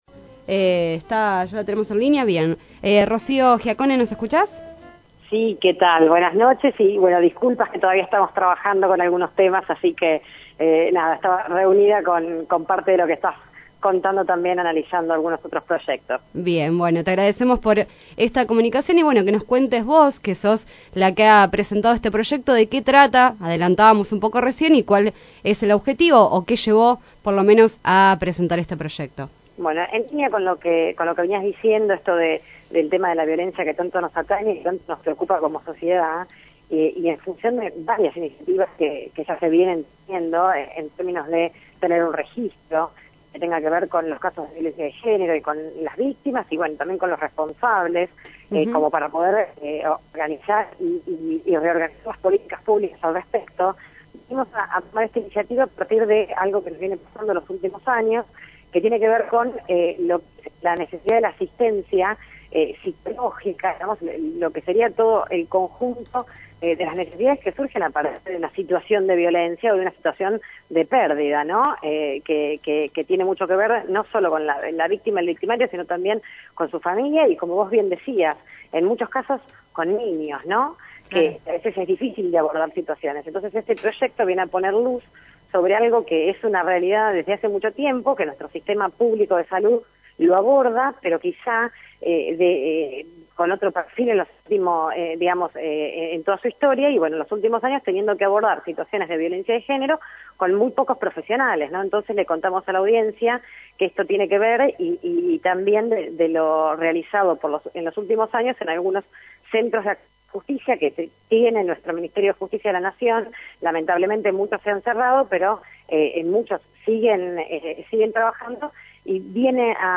Entrevista a Rocío Giaccone